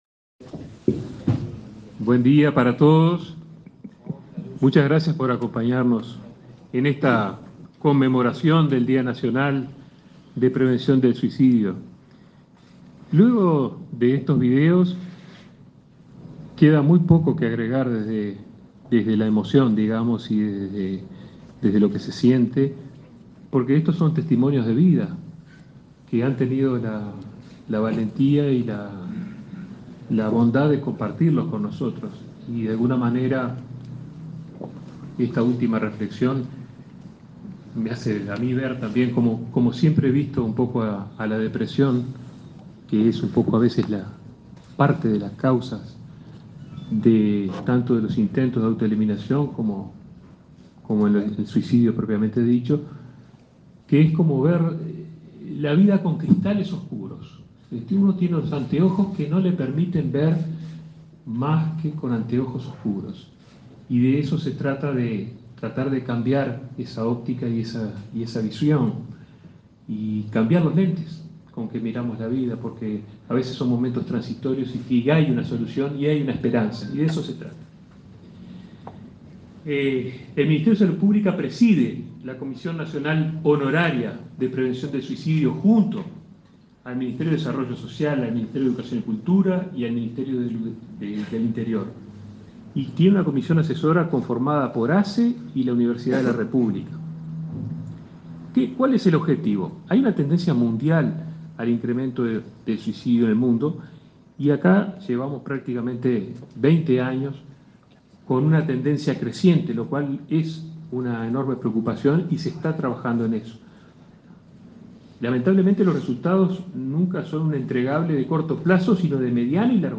Palabras de autoridades en el acto por el Día Nacional de Prevención del Suicidio
Palabras de autoridades en el acto por el Día Nacional de Prevención del Suicidio 15/07/2022 Compartir Facebook X Copiar enlace WhatsApp LinkedIn En el marco del acto por el Día Nacional de Prevención del Suicidio, el titular del Ministerio de Salud Pública (MSP), Daniel Salinas; su par de Desarrollo Social, Martín Lema, y el subsecretario del MSP, José Luis Satdjian, destacaron el trabajo que se lleva a cabo en torno a esta problemática.